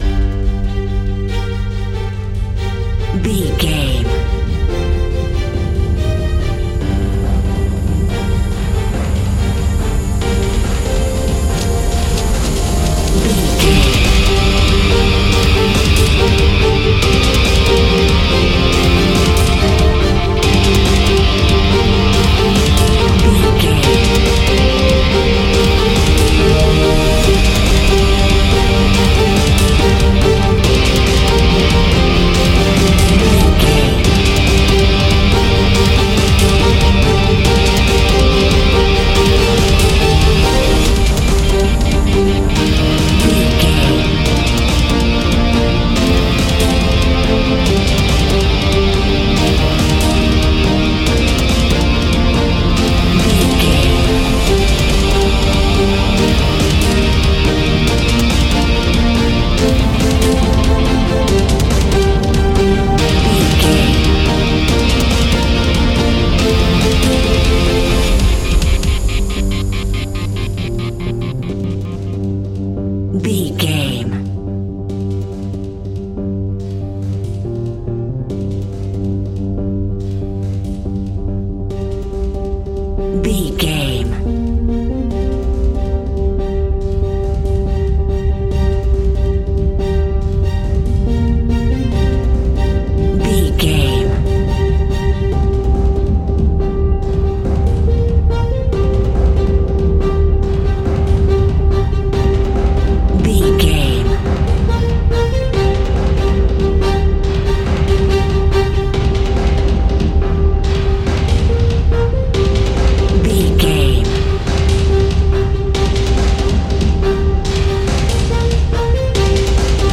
Aeolian/Minor
angry
aggressive
electric guitar
drums
bass guitar